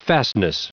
Prononciation du mot fastness en anglais (fichier audio)
Prononciation du mot : fastness